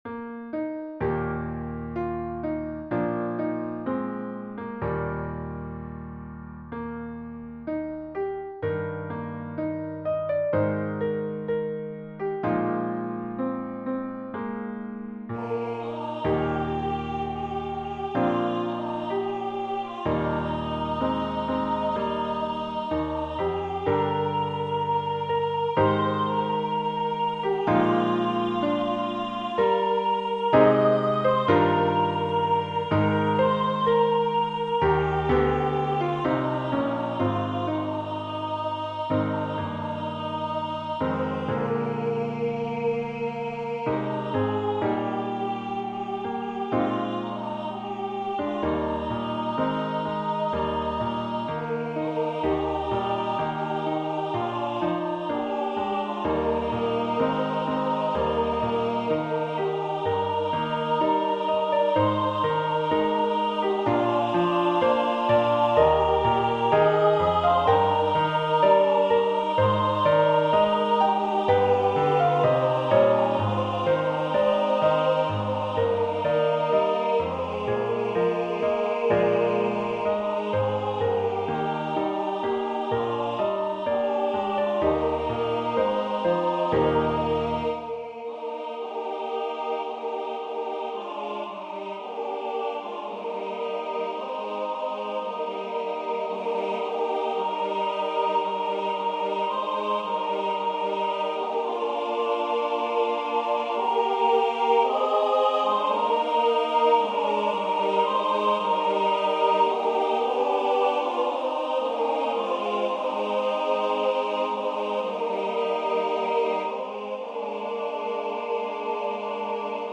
Voicing/Instrumentation: SSA